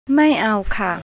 I don't want it. mai ow khah (khrahp) ไม่เอาค่ะ(ครับ)